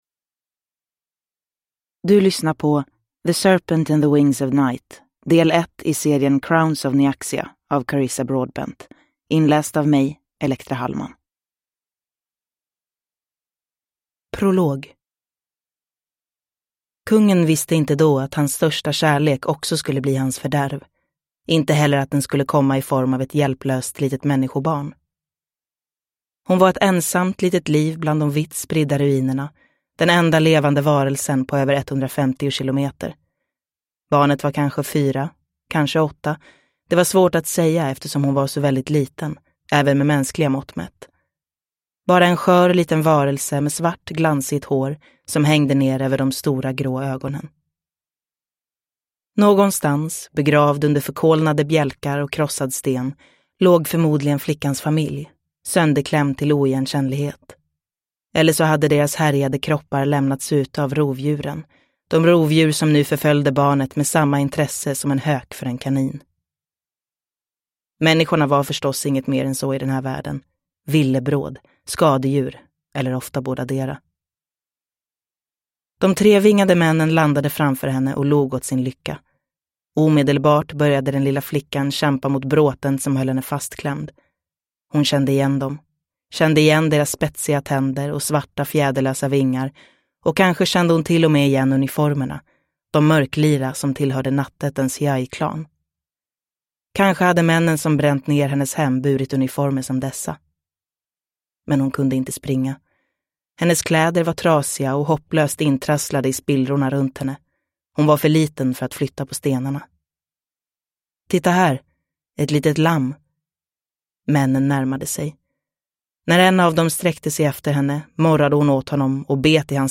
The Serpent & the wings of night (Svensk utgåva) – Ljudbok